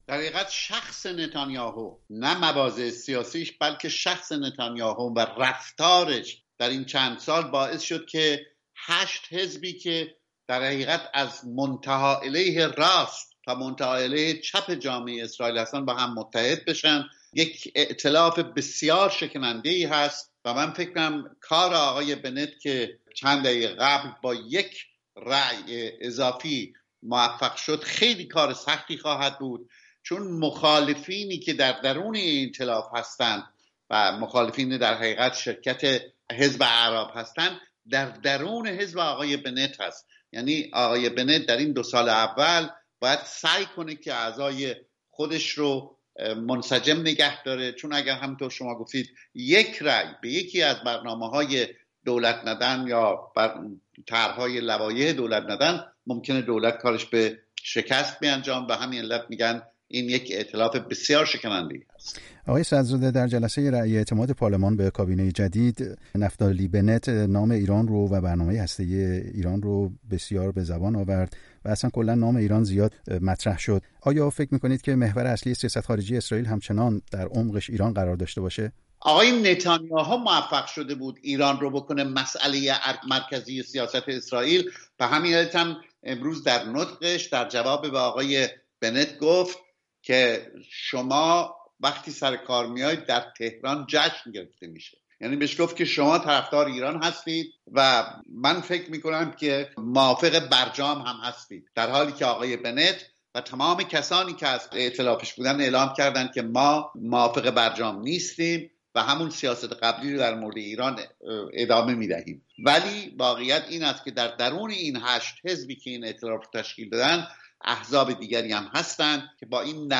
گفتگو کرده است.